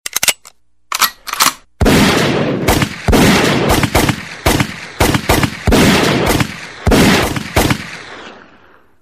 shotgun-ringtone_24790.mp3